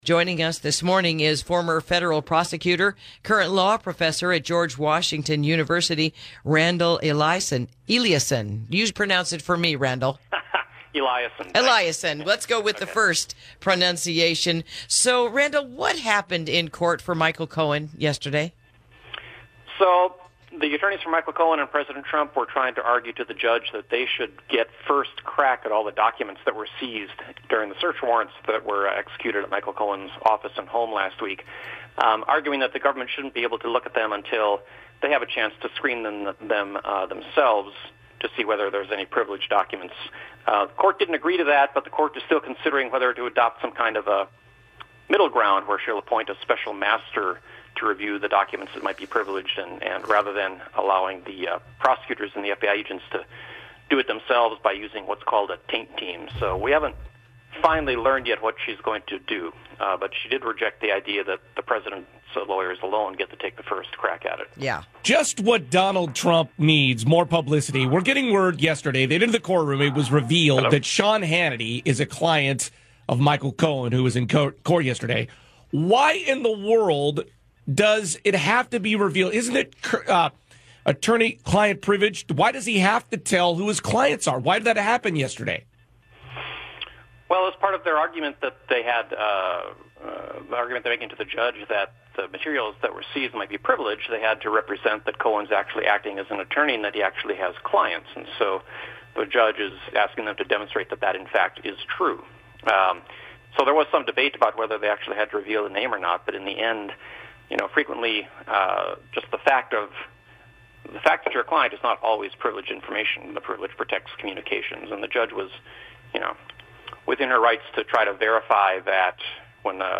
Interview: Michael Cohen and Monday’s Courtroom Revelations